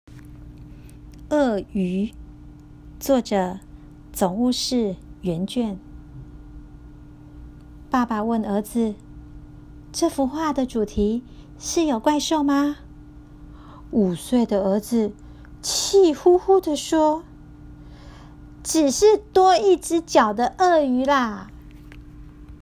語音導覽，另開新視窗
語音導覽-27.鱷魚.m4a